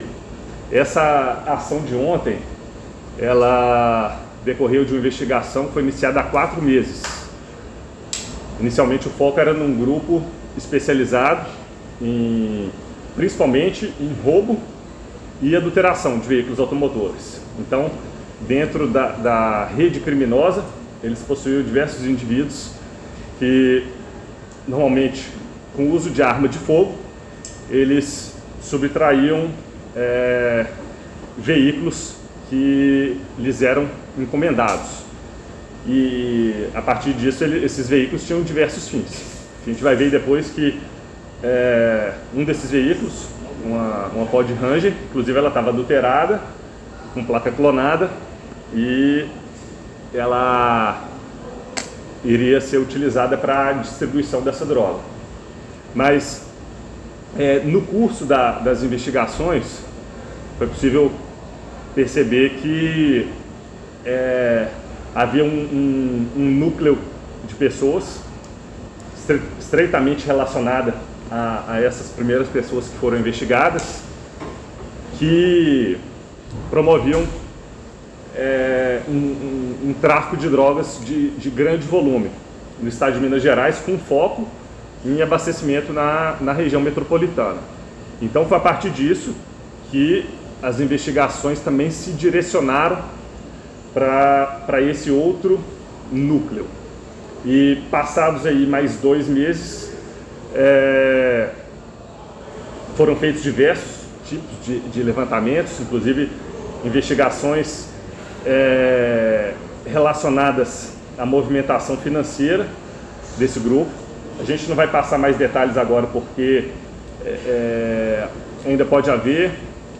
Coletiva.mp3